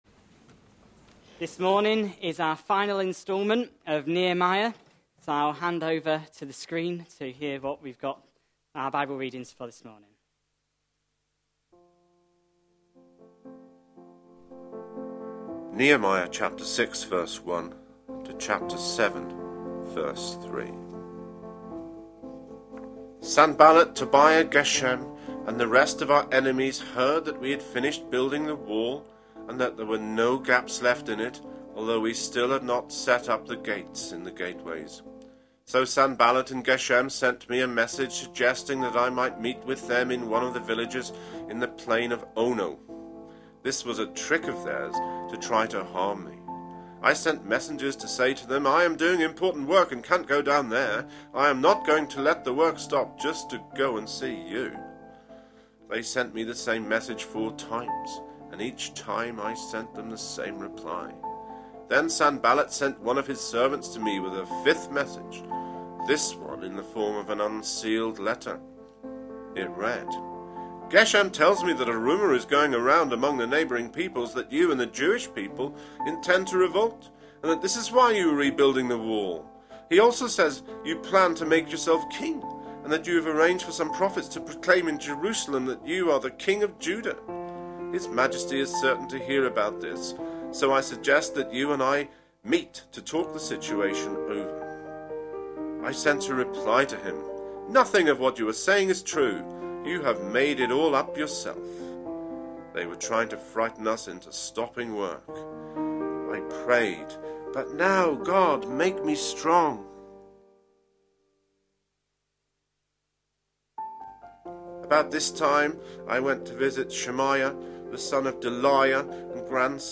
A sermon preached on 27th July, 2014, as part of our Another Brick In The Wall. series.